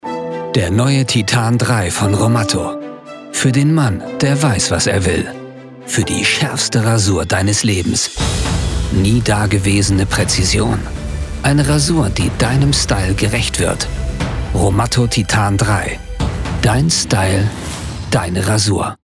Conversacional
Confiável
Amigáveis